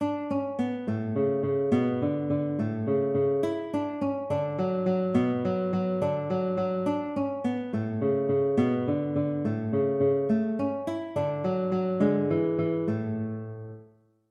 Joyful! Joyful! - Guitar Sight Reading Exercise | SightReadingMastery